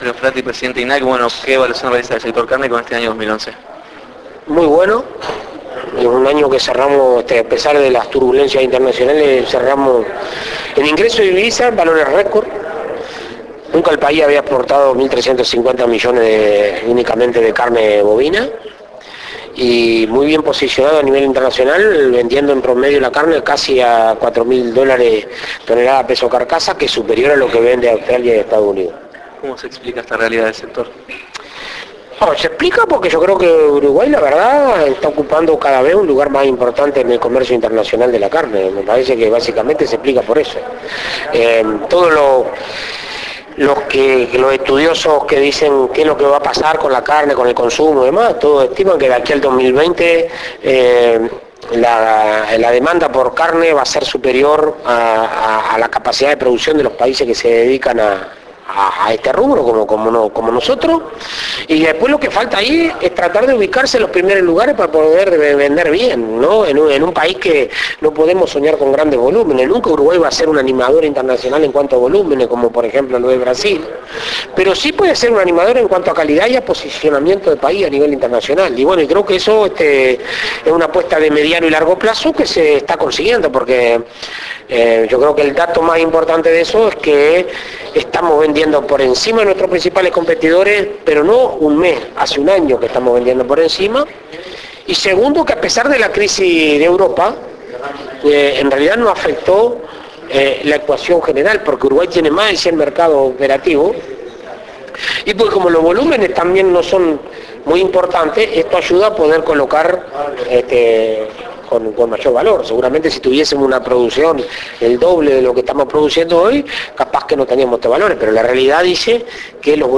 Presidente de INAC Dr. Alfredo Fratti Entrevista- cierre de año 2011 3:39 Mp3